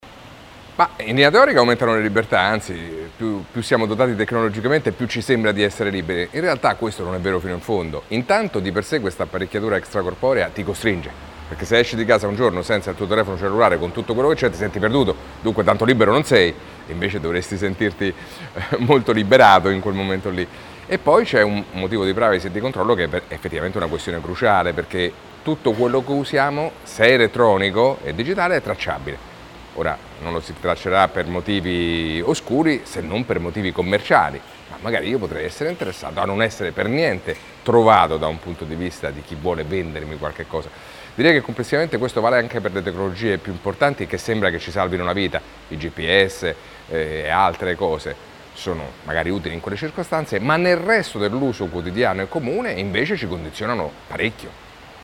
Oggi a EDUCA Mario Tozzi